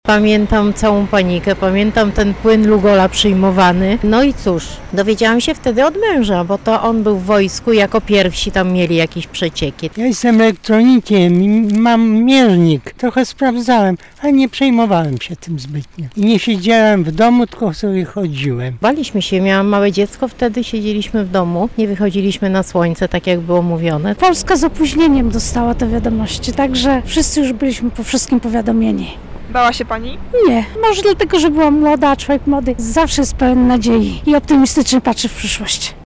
Zapytaliśmy mieszkańców Lublina, jak wspominają tamten czas:
mieszkańcy Lublina